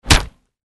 Звуки удара кулаком
На этой странице собраны реалистичные звуки удара кулаком — от легких хлопков до мощных нокаутирующих ударов.